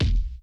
End Kick.wav